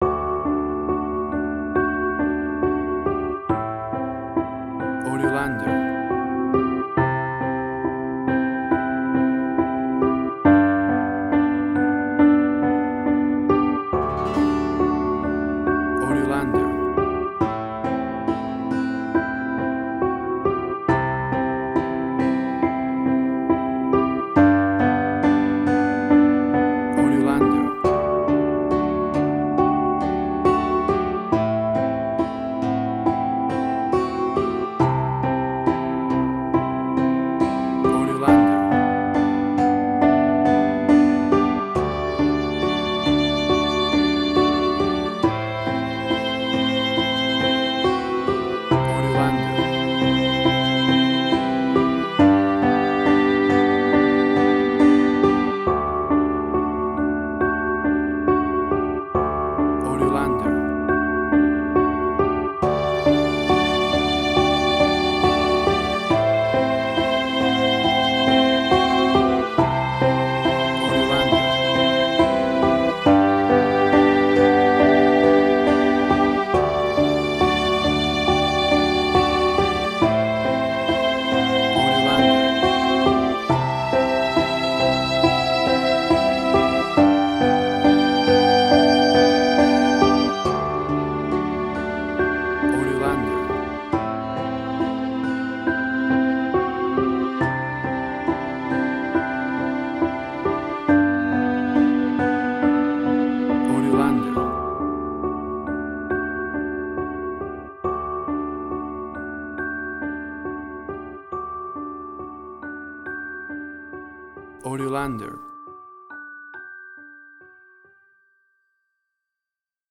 Suspense, Drama, Quirky, Emotional.
WAV Sample Rate: 16-Bit stereo, 44.1 kHz
Tempo (BPM): 70